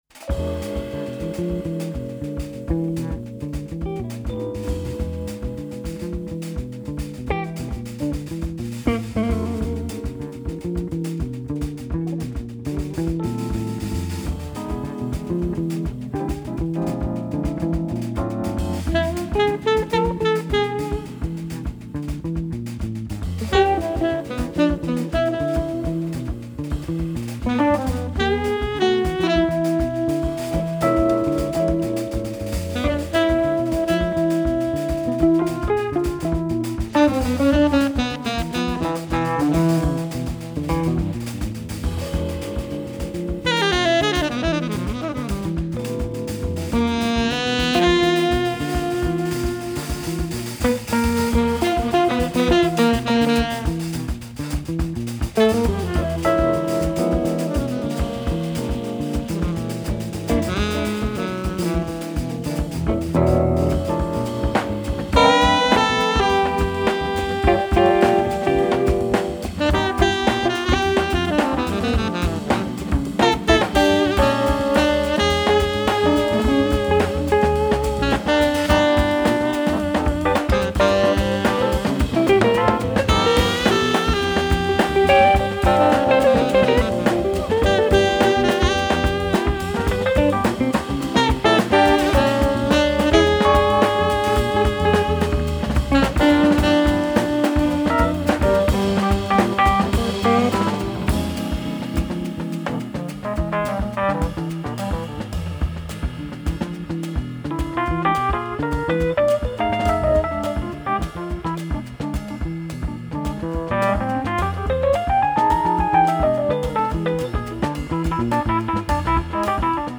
warm embracing groove
was recorded in Adelaide
piano and keys
tenor saxophone
upright bass
drums
guitar